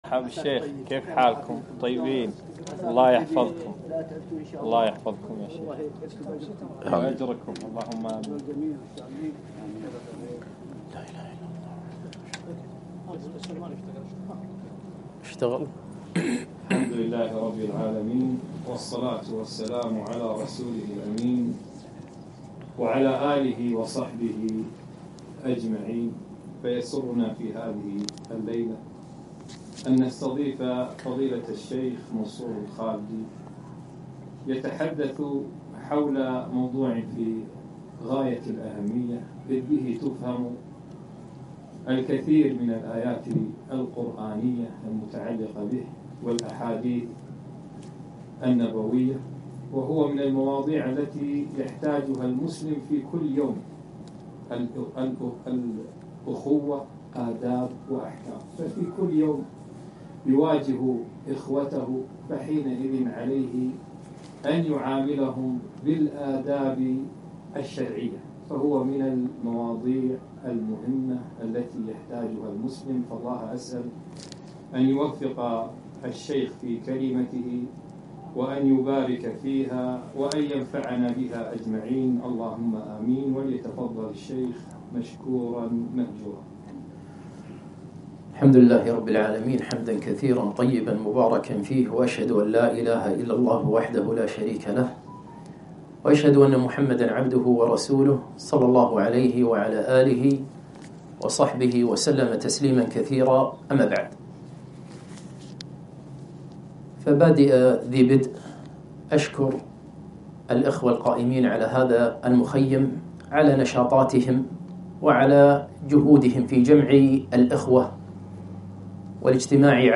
محاضرة - الأخوه آداب وأحكام